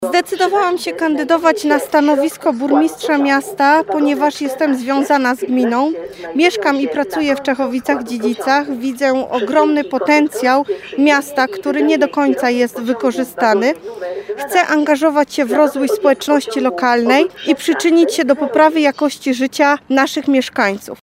Jej kandydaturę oficjalnie ogłosił na briefingu prasowym, który odbył się na dworcu kolejowym w tym mieście w Dniu Kobiet, były minister, a obecnie poseł Grzegorz Puda.